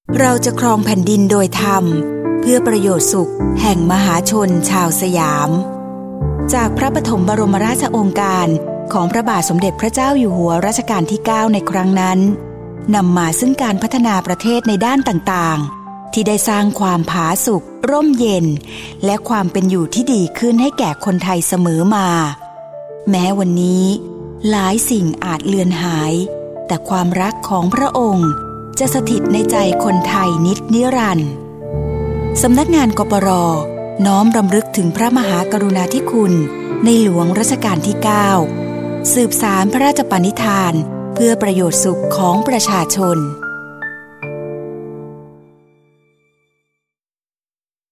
ปี 2568 : สปอตประชาสัมพันธ์ ตอนที่ 5 รำลึกถึงในหลวงรัชกาลที่ 9